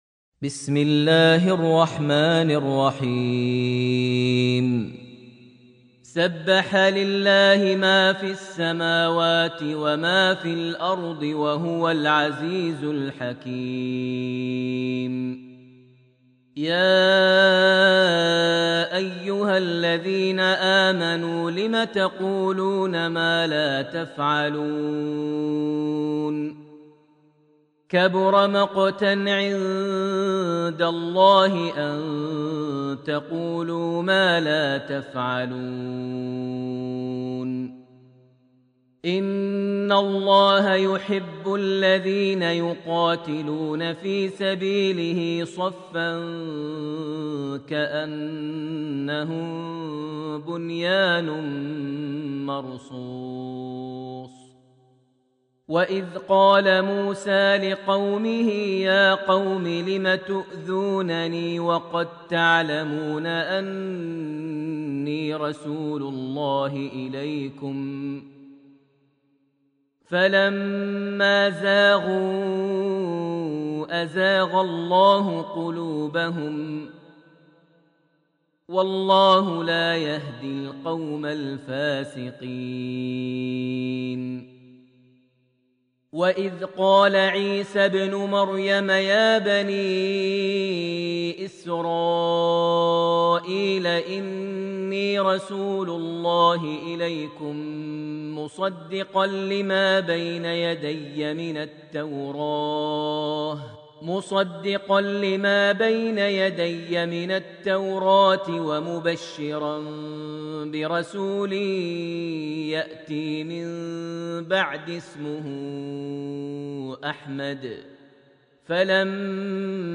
surat Al-Saff > Almushaf > Mushaf - Maher Almuaiqly Recitations